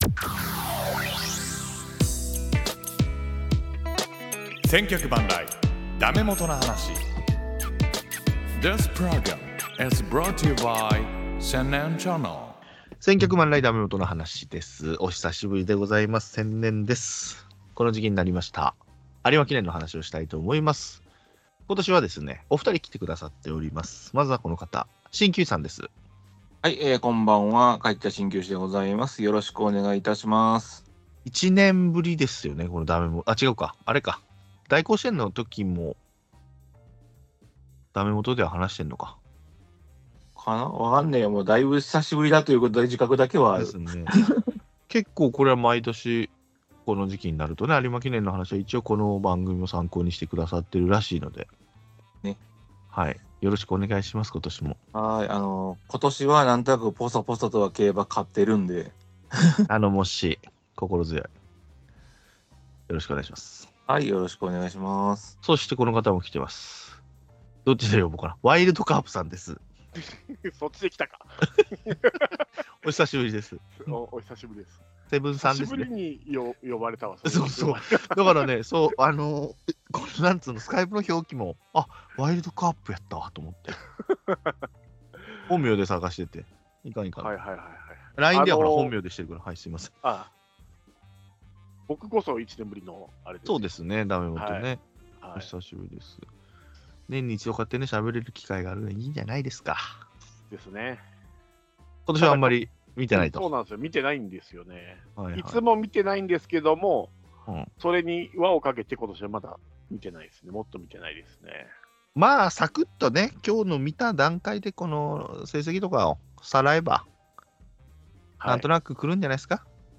素人３人がお届けするpodcast番組「千客万来！だめもとな話」サイト